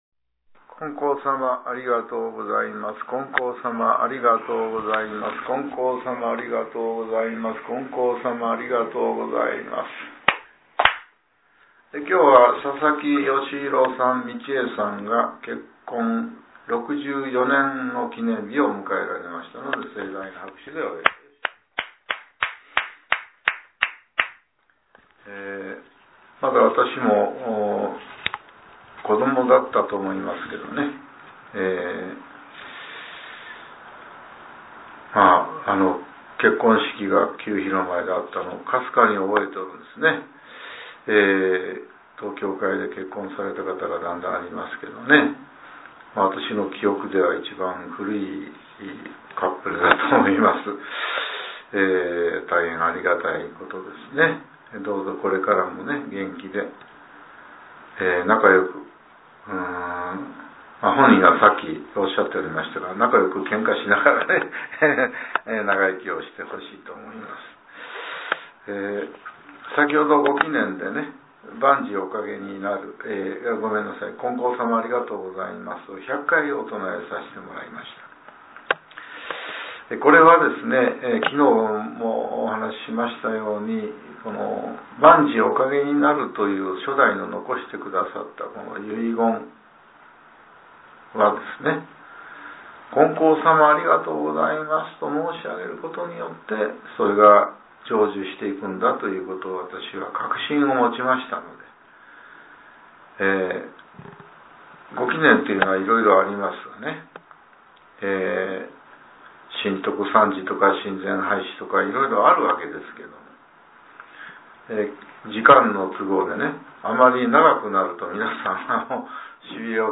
令和６年１１月２７日（朝）のお話が、音声ブログとして更新されています。